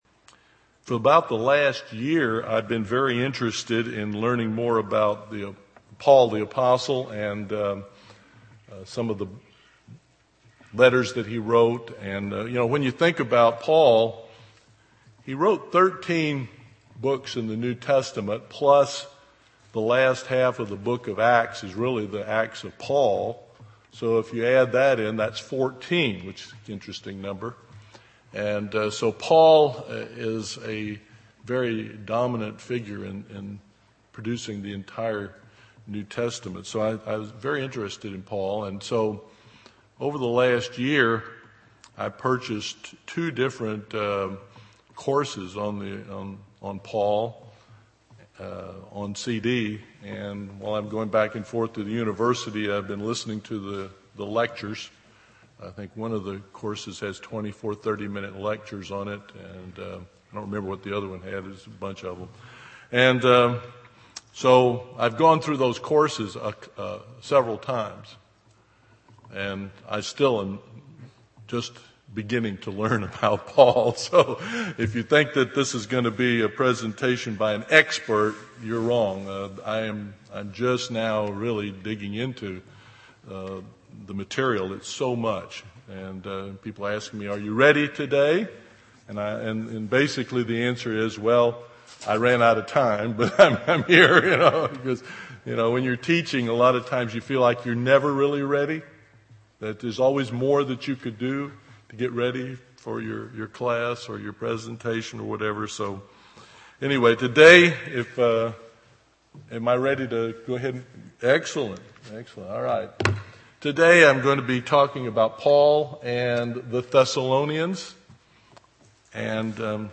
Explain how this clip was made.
Given in East Texas